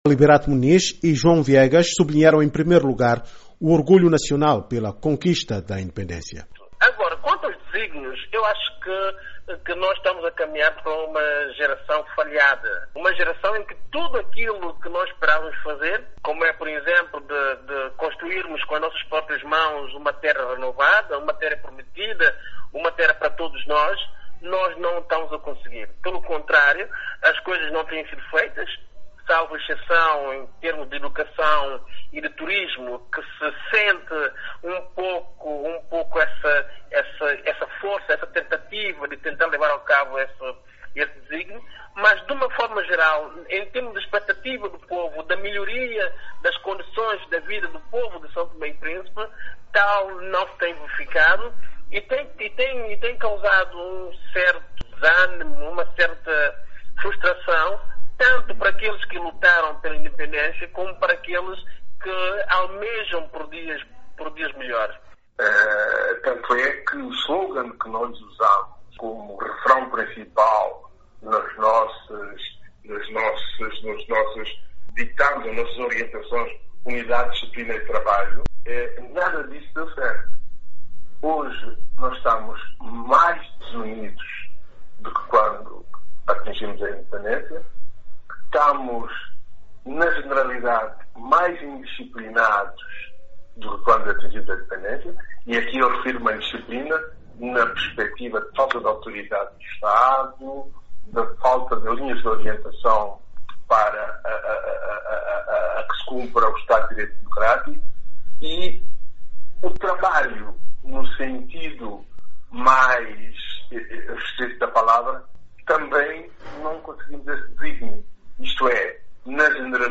Entrevistados